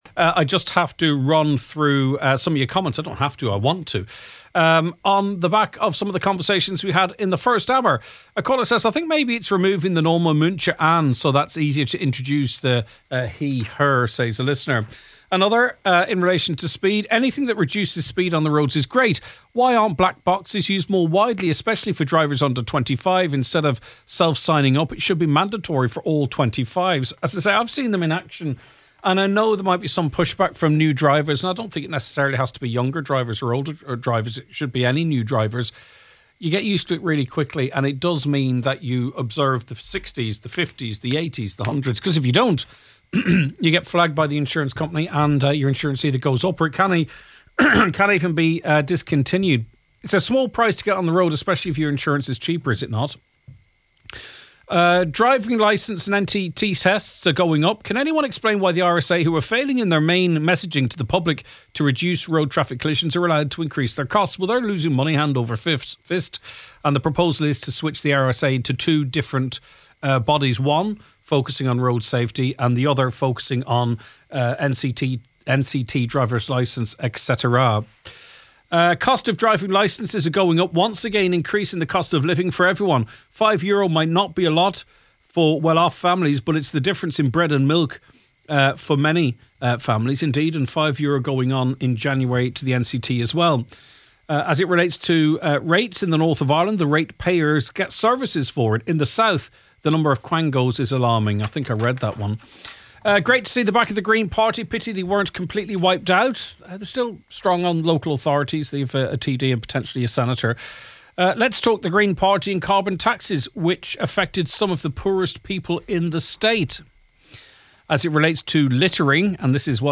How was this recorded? The Nine Til Noon is broadcast live each weekday between 9am to 12noon.